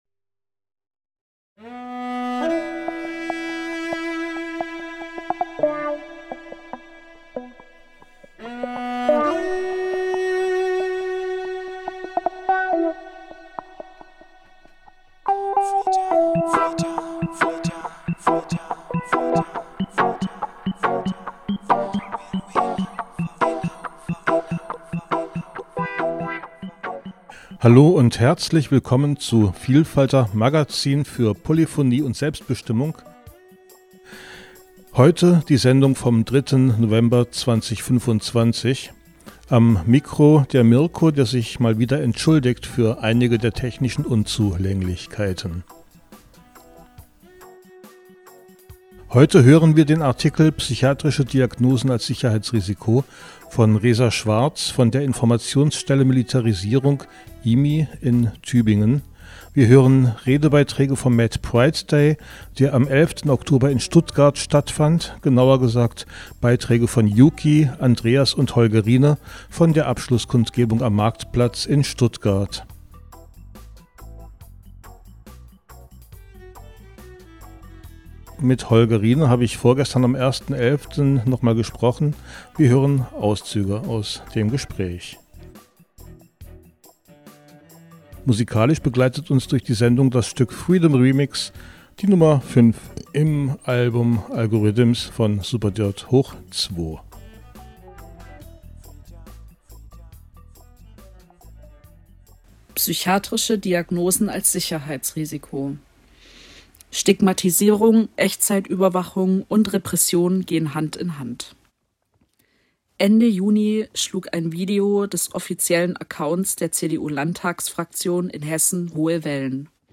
Außerdem hören wir drei Redebeiträge vom MadPrideDay in Stuttgart